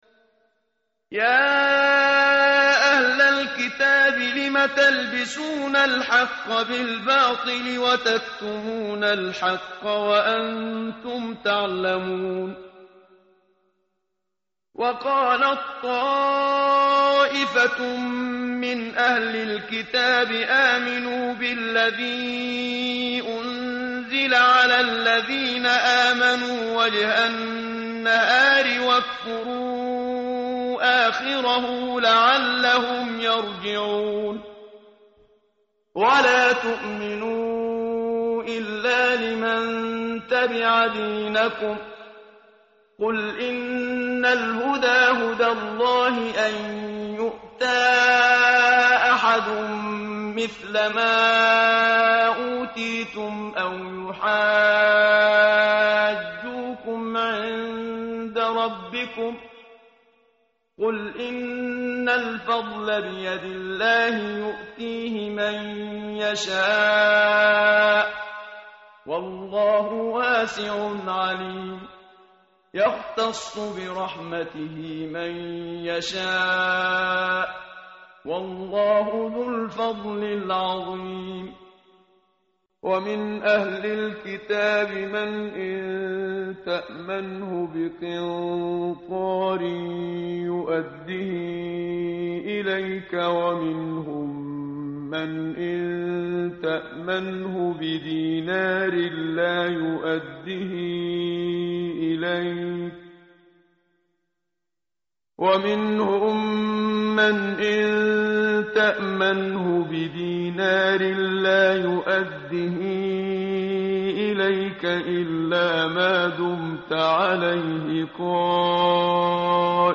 متن قرآن همراه باتلاوت قرآن و ترجمه
tartil_menshavi_page_059.mp3